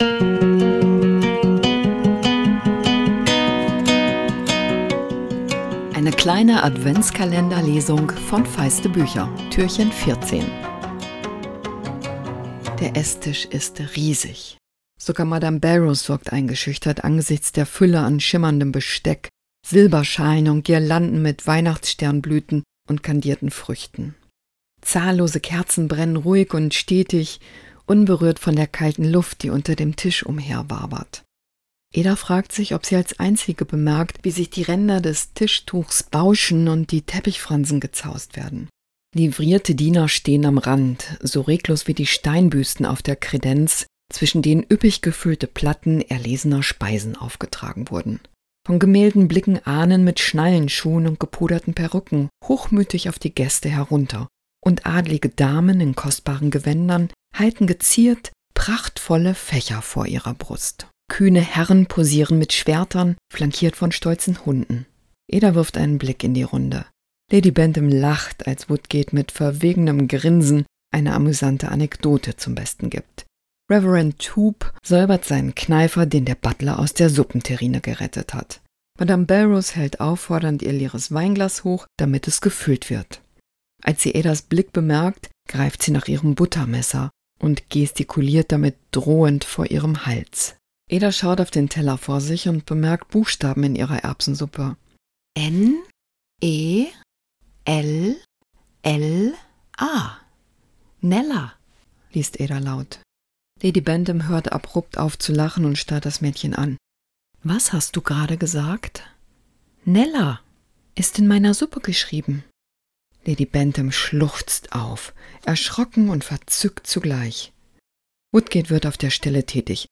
Adventskalender-Lesung 2024!
Wenn euch die Lesung Spaß macht, freue ich mich sehr, wenn ihr